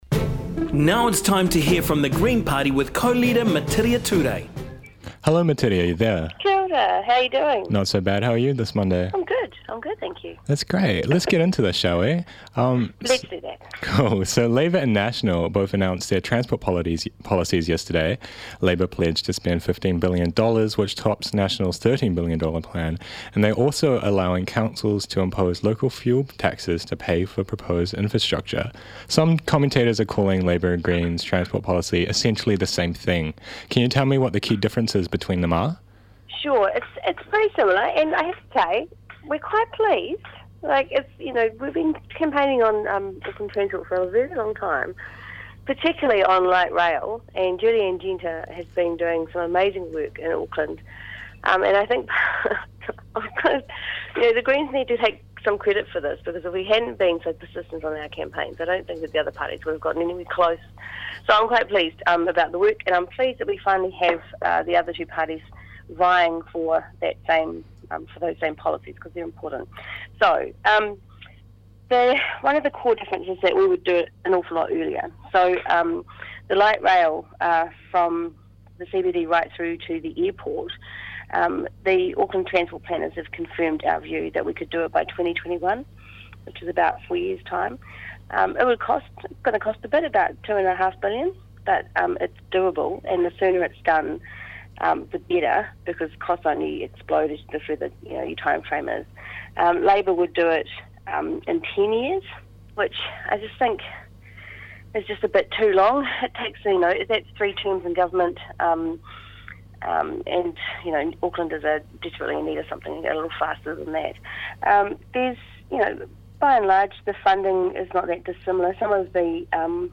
spoke to Metiria Turei for weekly chat with a Green Party Co-leader. They discussed the recent transport policies released by Labour and National and what her views on them are. Labour's policy released over the weekend is being seen as extremely similar to the Green Party's, which Turei is taking partial responsibility for.